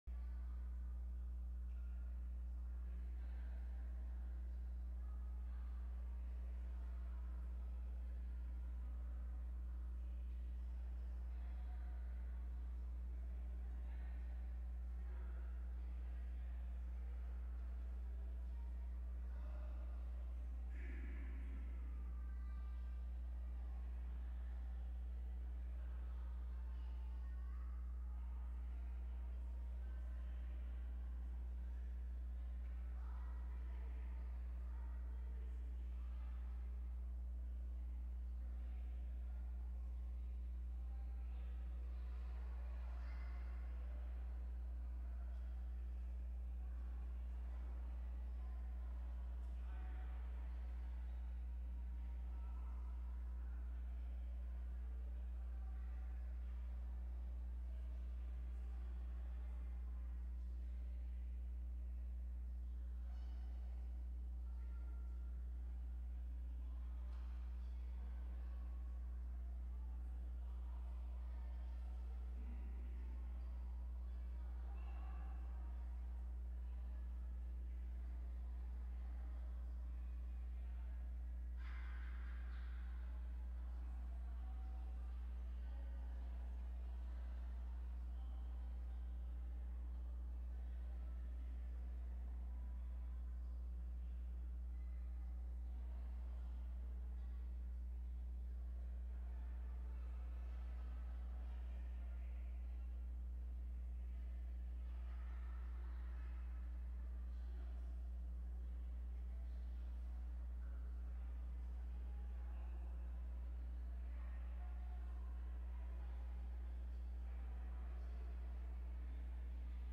STARTS AT 5:35 CHRISTMAS EVE SERVICE